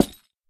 Minecraft Version Minecraft Version 1.21.5 Latest Release | Latest Snapshot 1.21.5 / assets / minecraft / sounds / block / hanging_sign / break1.ogg Compare With Compare With Latest Release | Latest Snapshot
break1.ogg